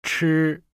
• Ch: Bật hơi mạnh. Vị trí lưỡi giống âm zh, nhưng bật hơi mạnh ra ngoài.
Âm phải vang và rõ ràng, phải cảm nhận được luồng hơi bật mạnh ra ngoài.
6.2.-ch-nam.mp3